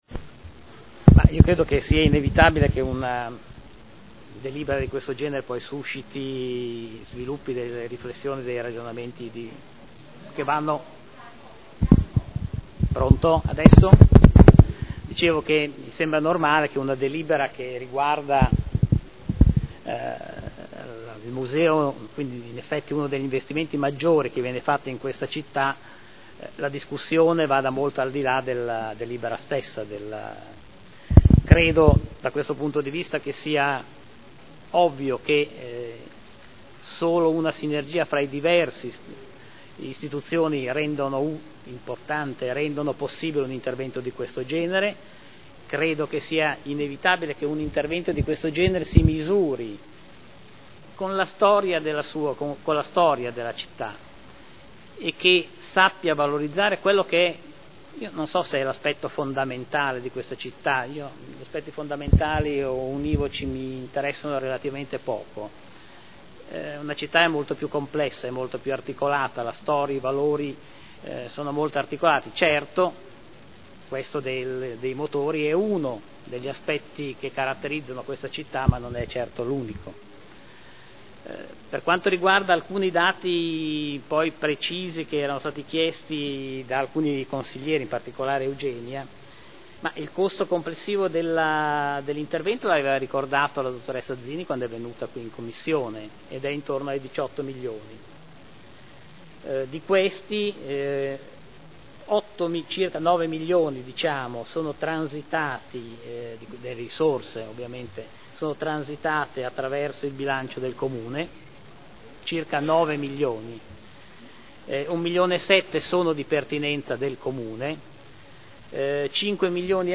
Seduta del 20/02/2012 Conclusioni al dibattito su Delibera: Proroga concessione di garanzia fideiussoria a favore di Unicredit Banca Spa per apertura di credito e mutuo della Fondazione Casa di Enzo Ferrari Museo (Commissione consiliare del 7 febbraio 2012)